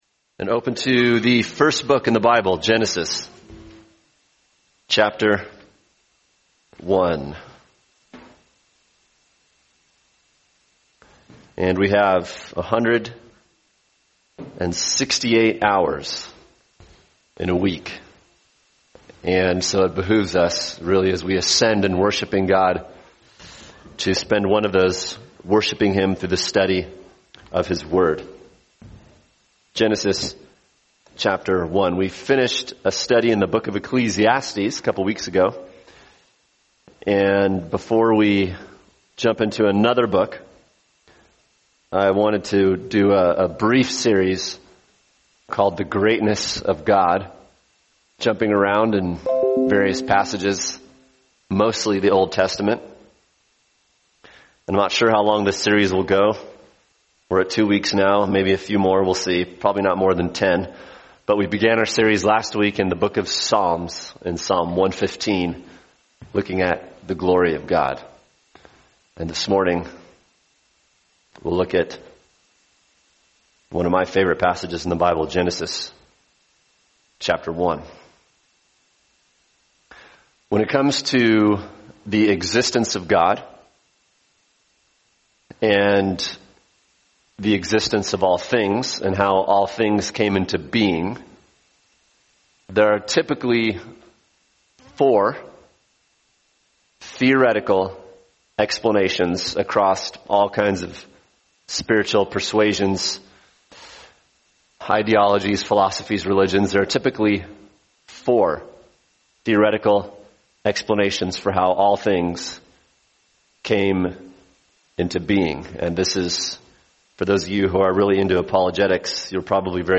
[sermon] Genesis 1 – The Greatness of God: The God Who Created | Cornerstone Church - Jackson Hole